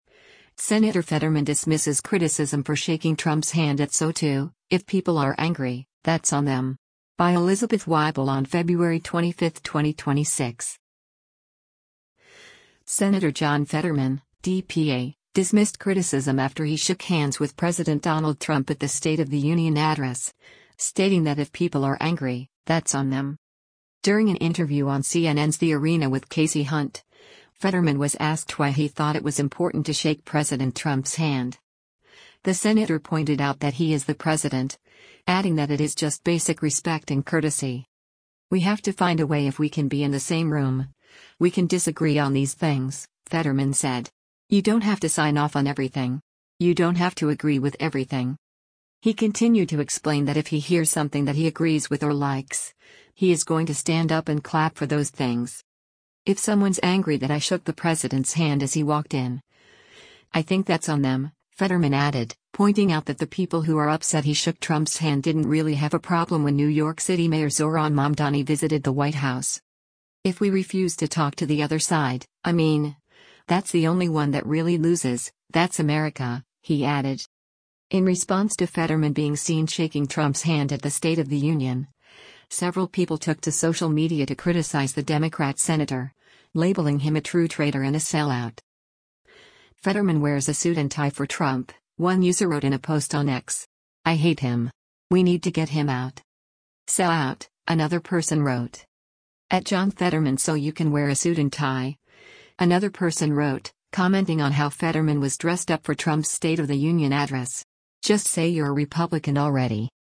During an interview on CNN’s The Arena with Kasie Hunt, Fetterman was asked why he thought it was “important to shake President Trump’s hand.”